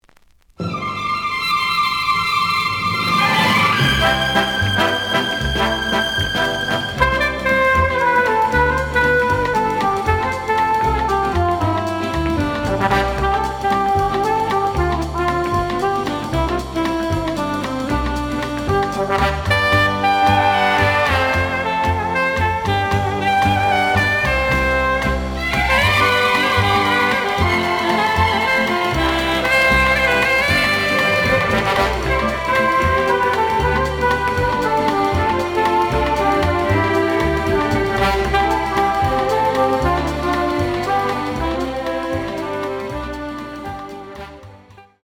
The audio sample is recorded from the actual item.
●Genre: Cool Jazz